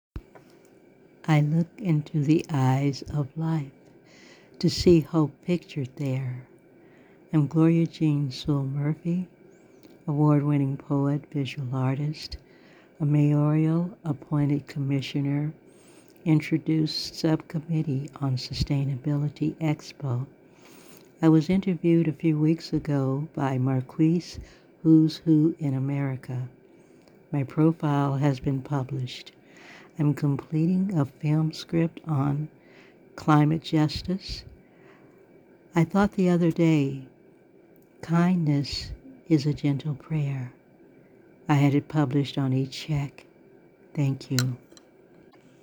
Voice Talent
Soft, pleasant voice, skilled in conveying genuine emotions.